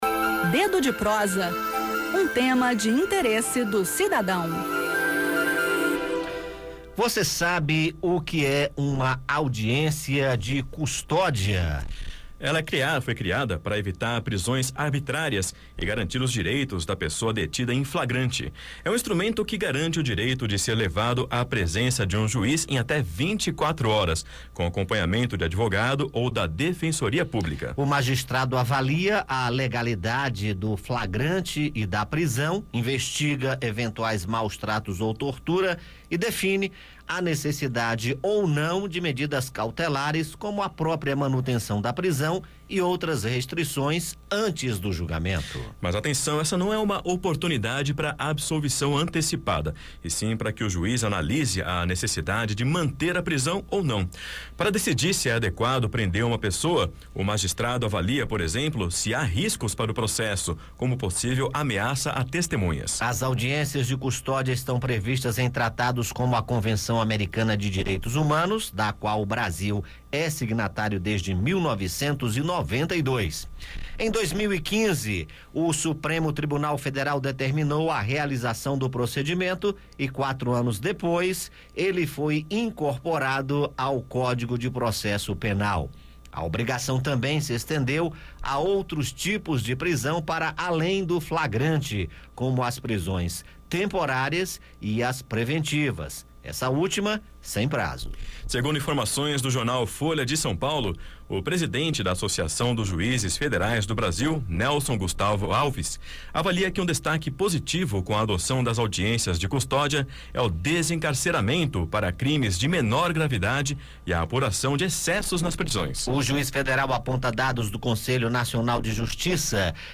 Um instrumento importante para reduzir esses números são as audiências de custódia, uma vez que o juíz pode decidir, em até 24h, por medidas cautelares, prisão domiciliar e uso de tornozeleiras eletrônicas. Confira os detalhes no bate-papo.